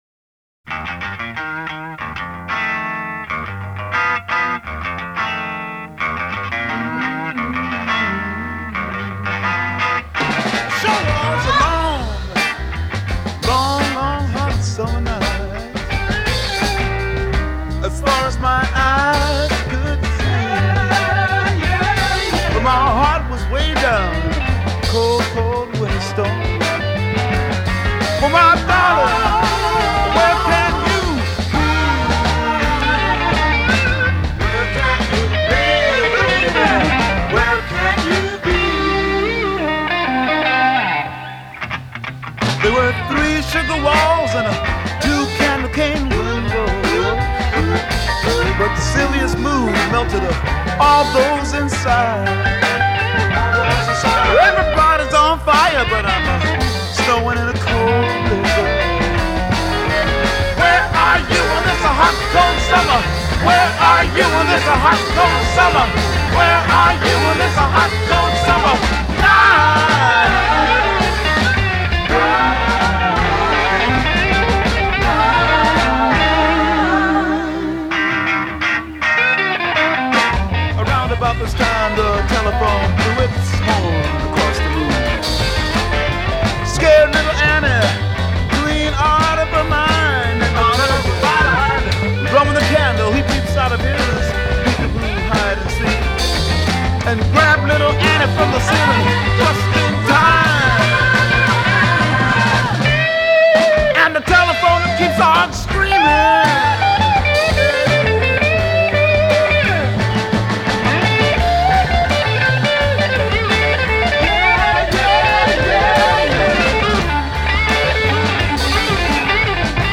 Жанр: Psychedelic, Blues Rock